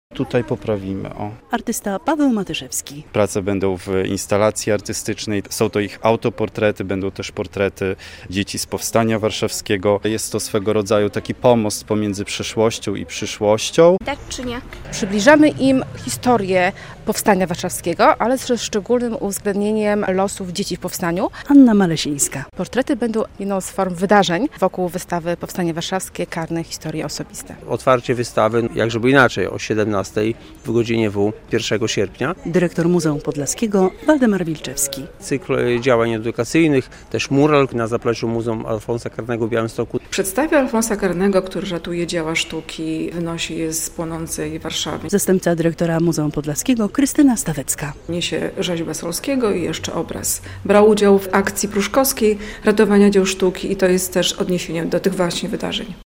Przygotowania do otwarcia wystawy "Powstanie Warszawskie - Karny - Historie osobiste" - relacja